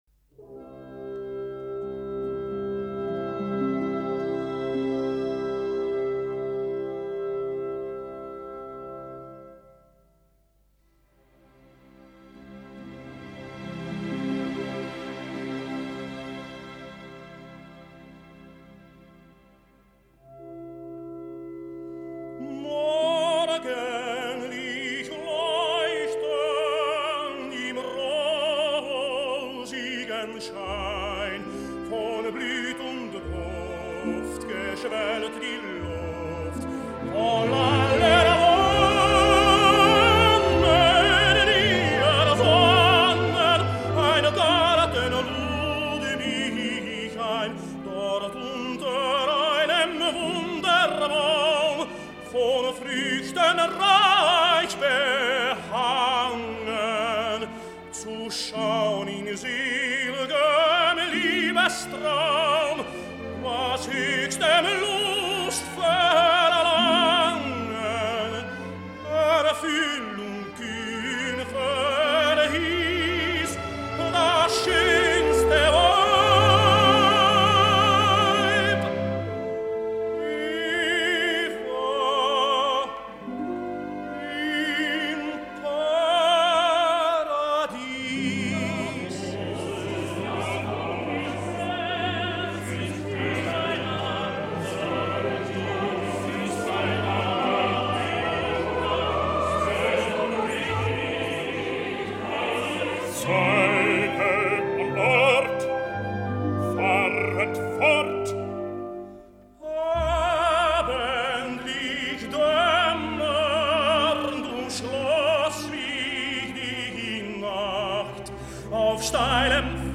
I ara la proposta participativa, us vull deixar el mateix fragment pel tenor hongarès Sándor Kónya en la magnífica gravació de Rafael Kubelik per tal de que sense que jo hi afegeixi res més, s’evidenciïn les immenses distàncies que separen ambdues interpretacions, i per tant aquesta seria la meva proposta per tal de que el tenor ignot s’escoltés la versió del tenor hongarès.